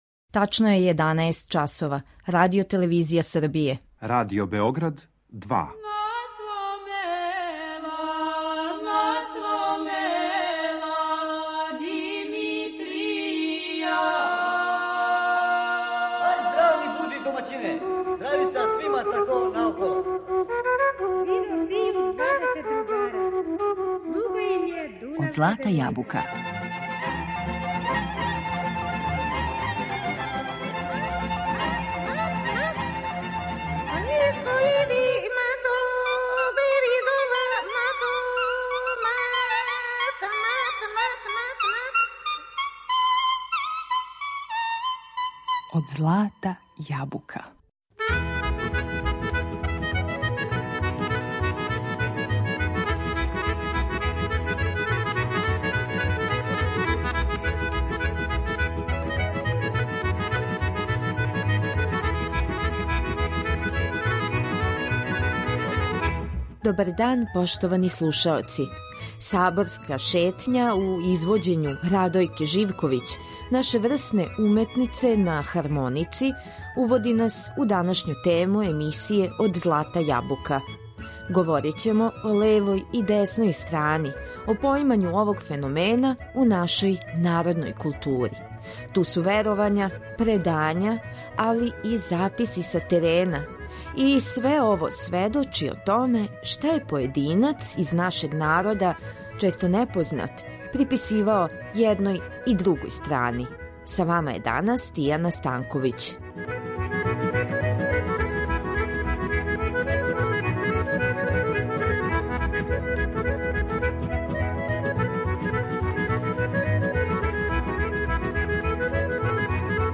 Веровања, изреке и остале појединости из литературе посвећене овом темом илустроваћемо нашим најлепшим народним песмама и колима.